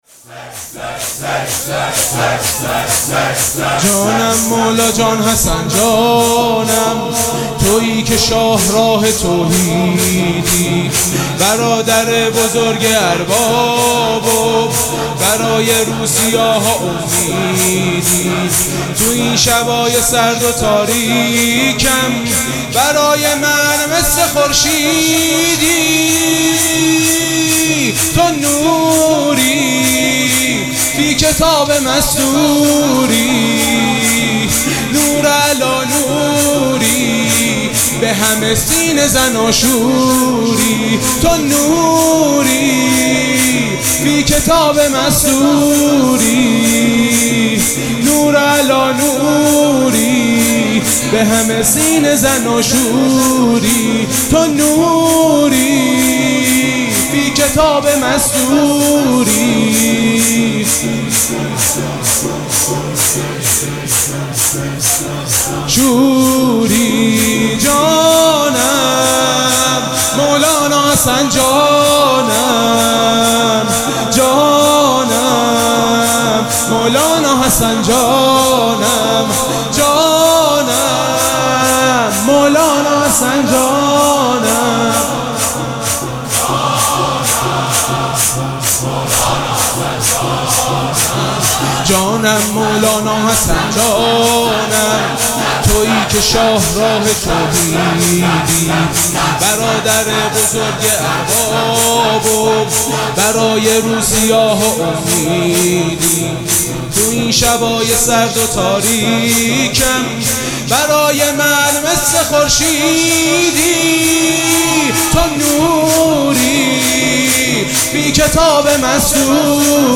مراسم عزاداری شب ششم محرم الحرام ۱۴۴۷
شور
مداح